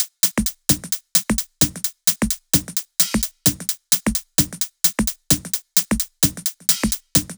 VFH2 130BPM Comboocha Kit 8.wav